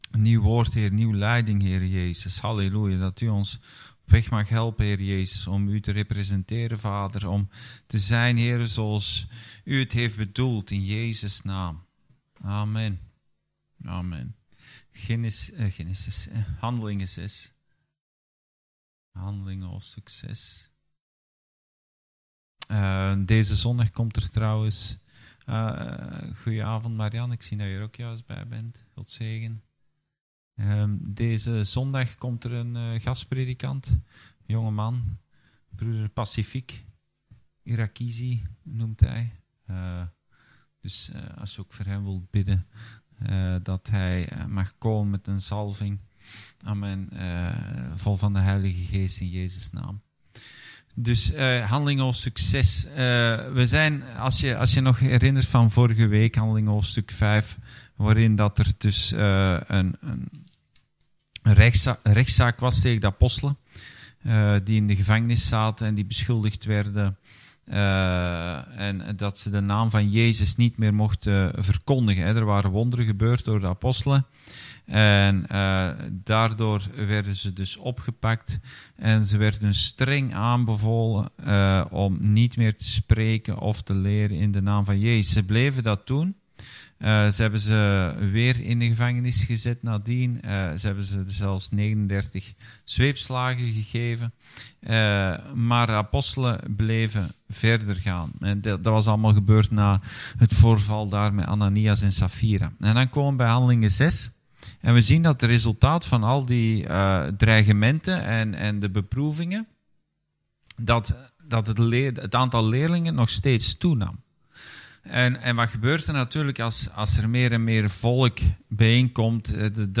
Bijbelstudie: Handelingen 6-7